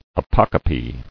[a·poc·o·pe]